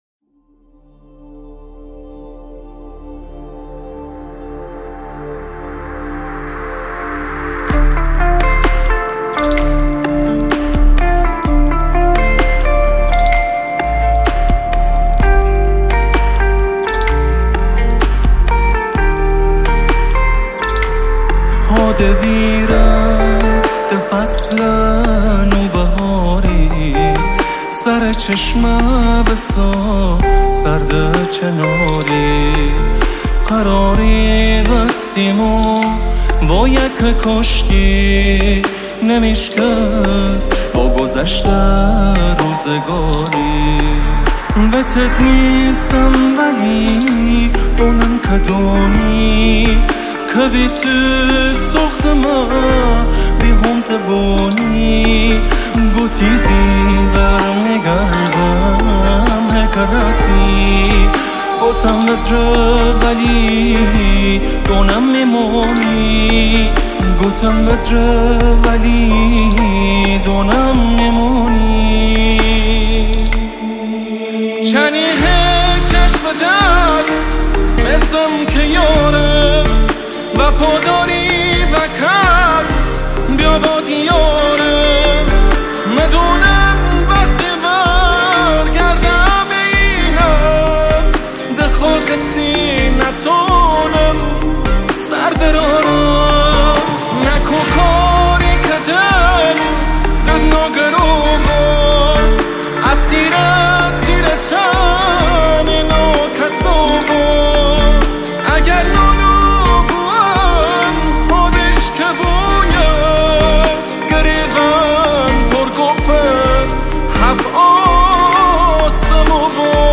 آرامش یعنی این صدا